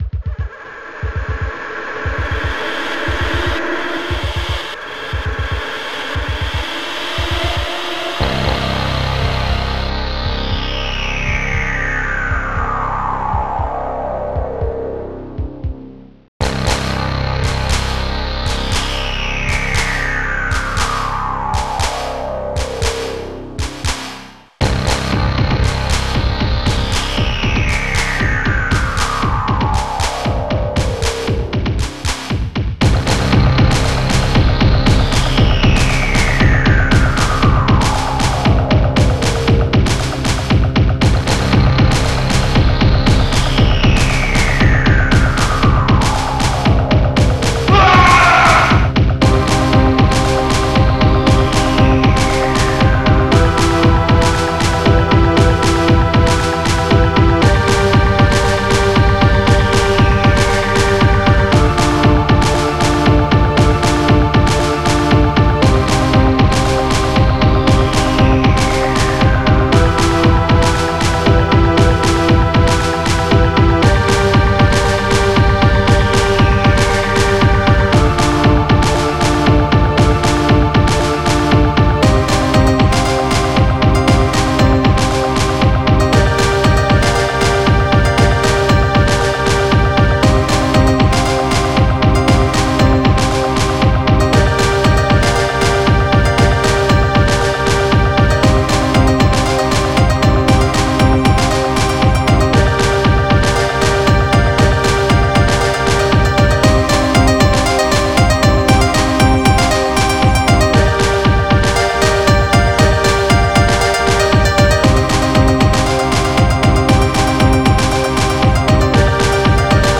ECHOCLAP
PIANO